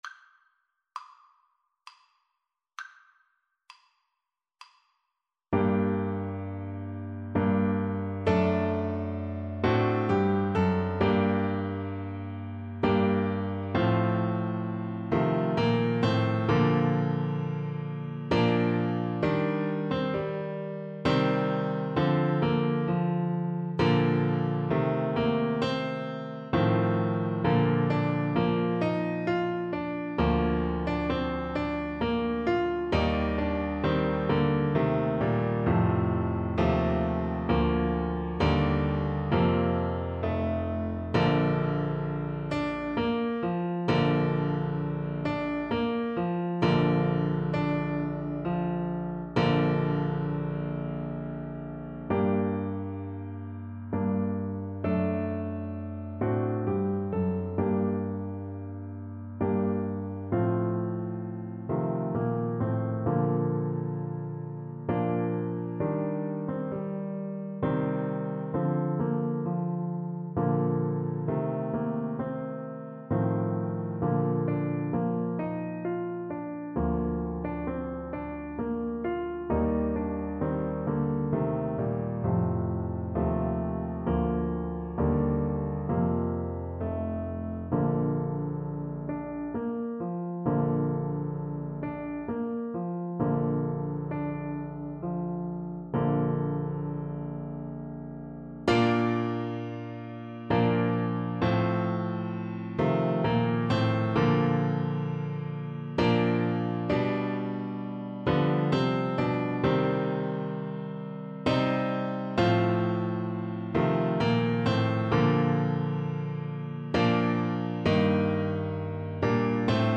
Play (or use space bar on your keyboard) Pause Music Playalong - Piano Accompaniment Playalong Band Accompaniment not yet available transpose reset tempo print settings full screen
Tuba
G minor (Sounding Pitch) (View more G minor Music for Tuba )
3/4 (View more 3/4 Music)
Classical (View more Classical Tuba Music)